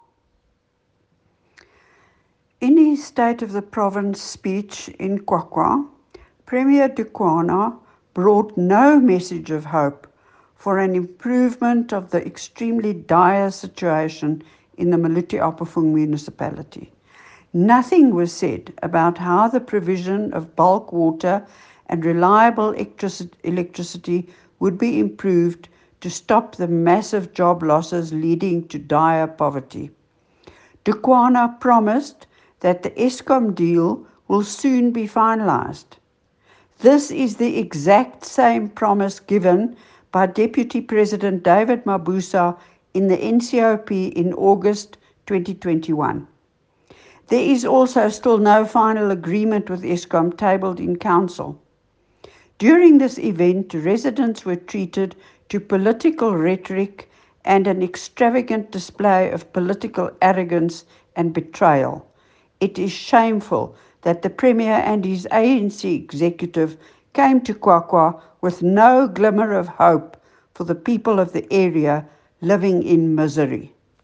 Afrikaans soundbites by Leona Kleynhans MPL and